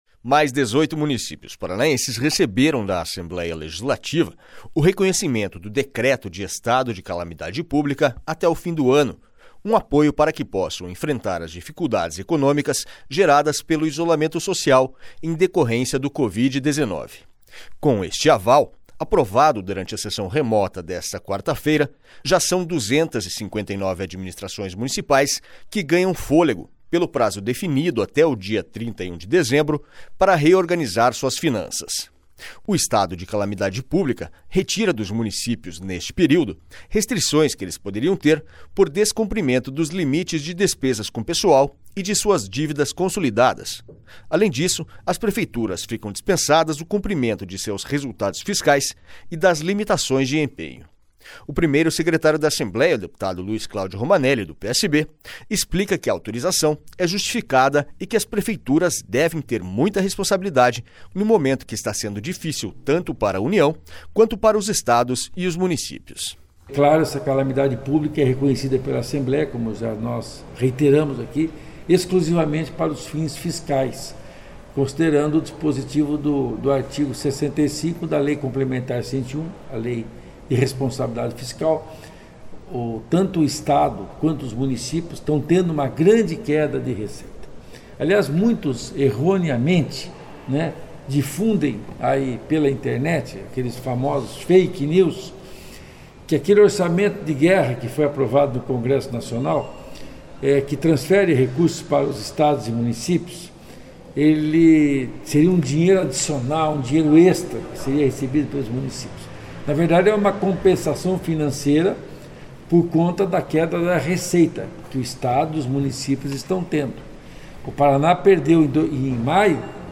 SONORA LUIZ CLÁUDIO ROMANELLI